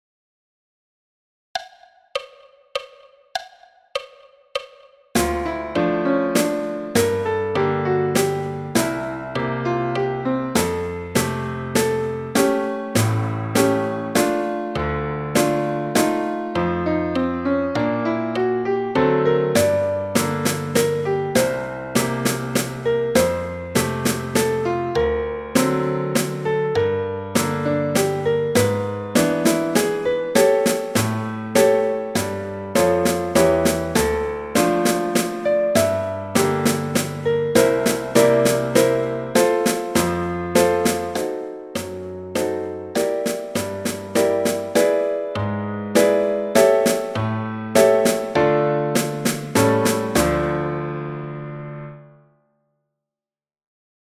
Etude n°2 – Londeix – caisse et piano à 100 bpm